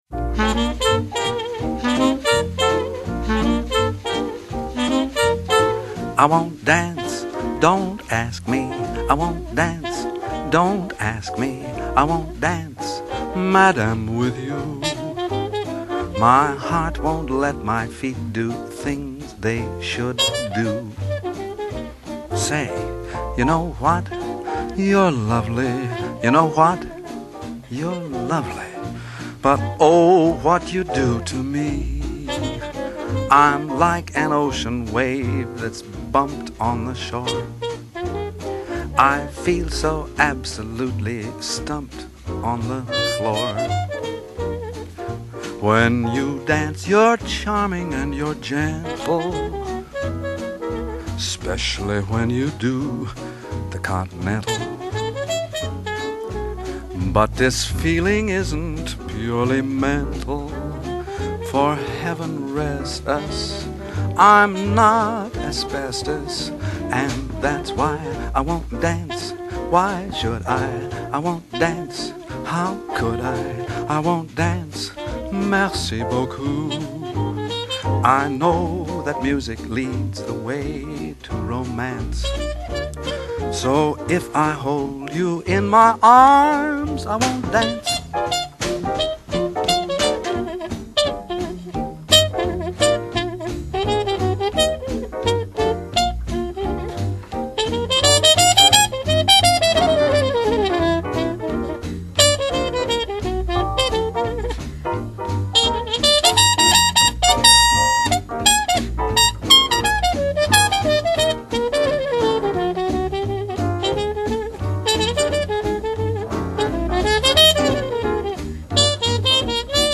畅销法国的豪华版爵士情歌经典